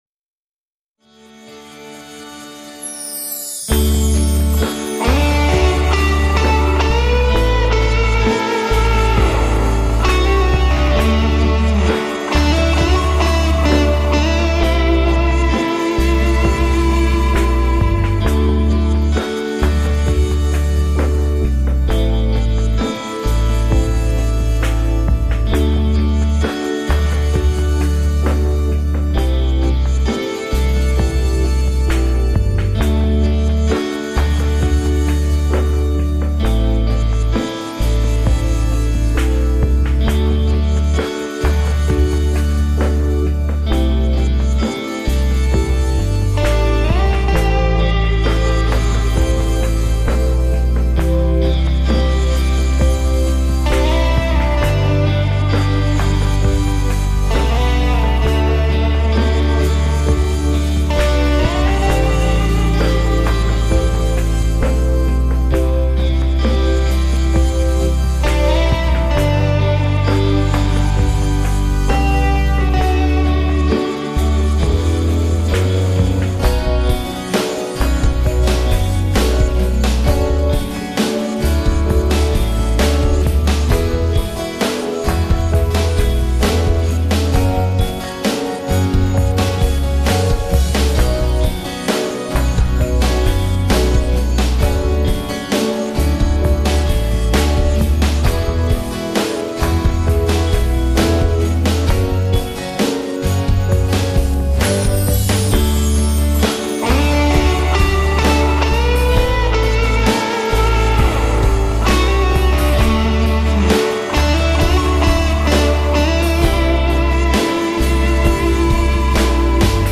base testo